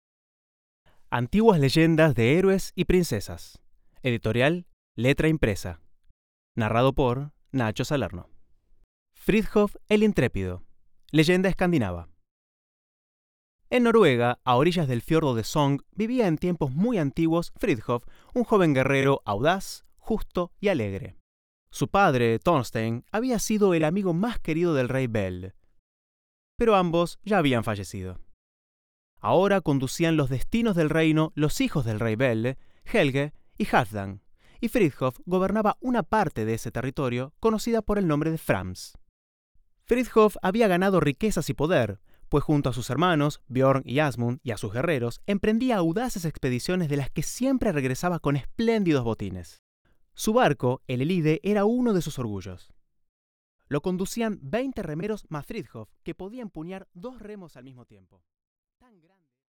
Muestra de audiolibro